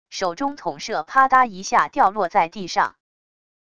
手中筒射啪嗒一下掉落在地上wav音频